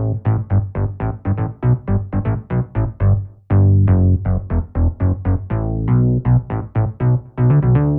34 Bass PT2.wav